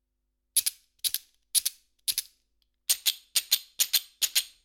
Klangbeispiele - Sound Samples
The last sound sample combines several instruments.
File 1 File 2 File 3 File 4 File 5 Das letzte Klangbeispiel ist eine Kombination mehrerer Instrumente.